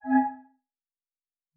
pgs/Assets/Audio/Sci-Fi Sounds/Interface/Error 18.wav at master
Error 18.wav